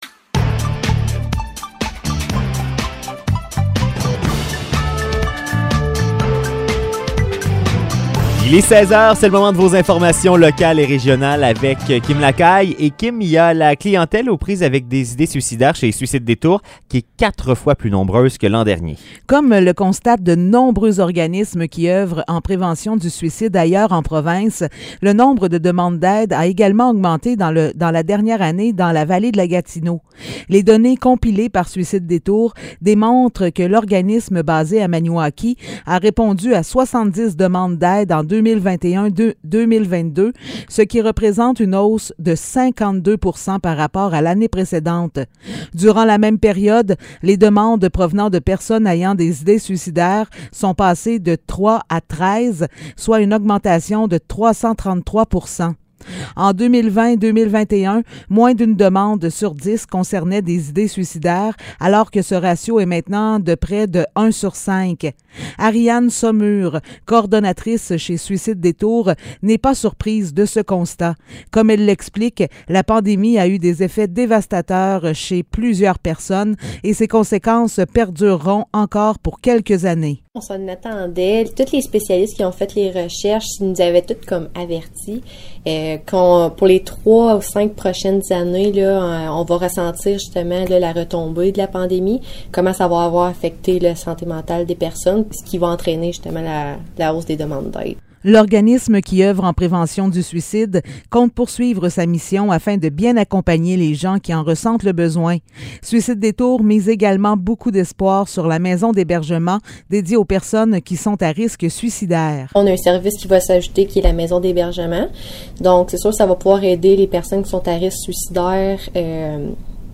Nouvelles locales - 27 juillet 2022 - 16 h